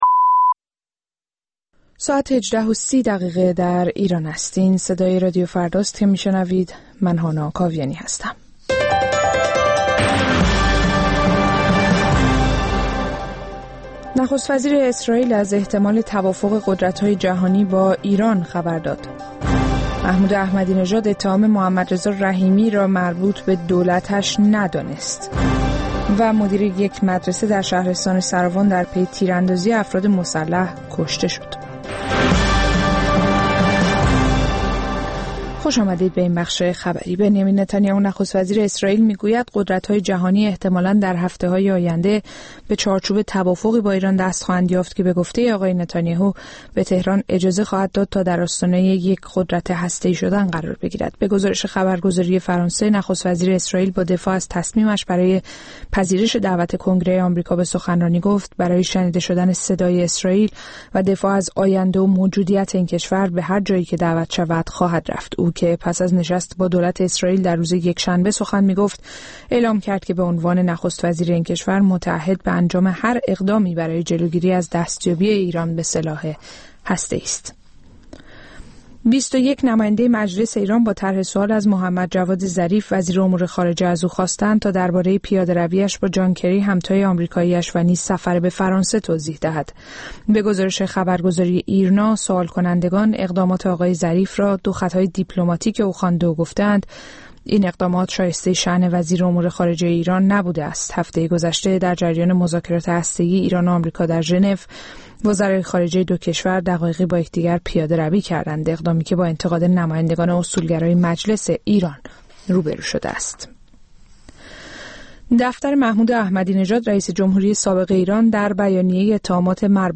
دات‌کام، مجله هفتگی رادیو فردا در حوزه اینترنت، دنیای مجازی و تکنولوژی‌های جدید است. این برنامه همچنین تلاش دارد از زاویه‌ای متفاوت به شبکه‌های مجازی و رفتار کاربران ایرانی در این شبکه‌ها بپردازد.